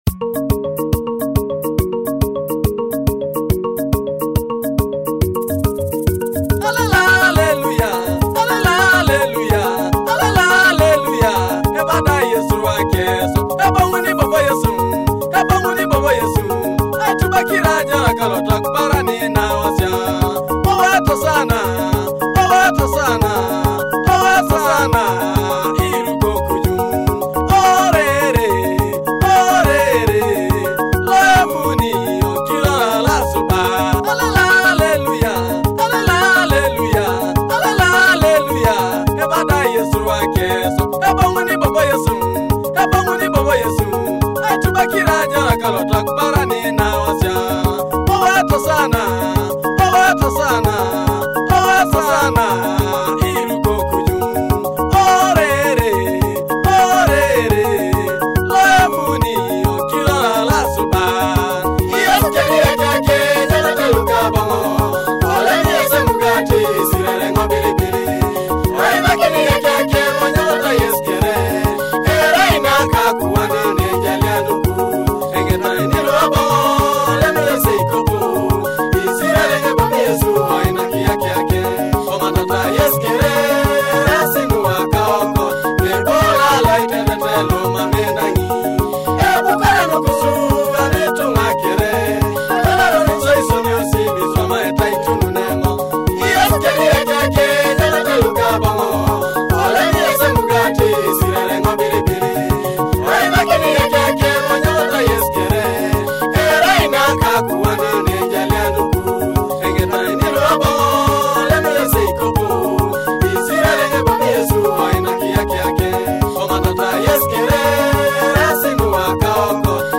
Teso gospel music